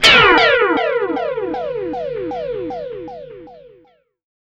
GUITARFX 7-L.wav